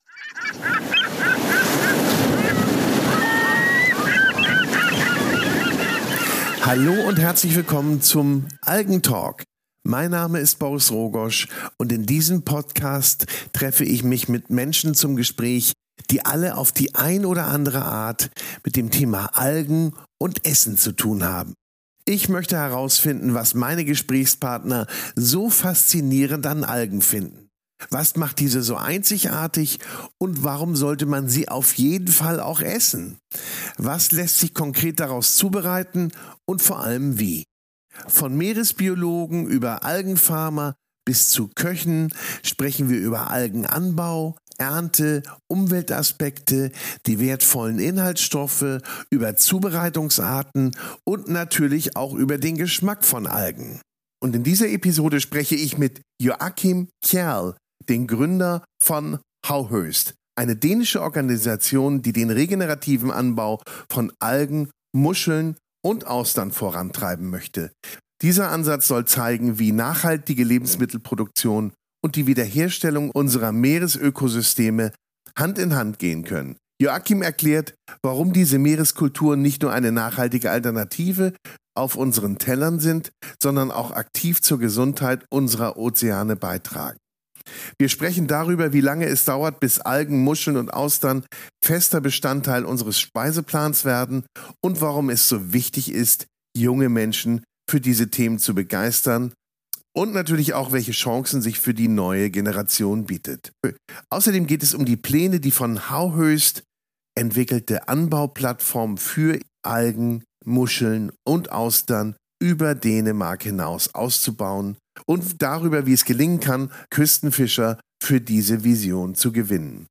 Das heutige Gespräch findet in englischer Sprache statt.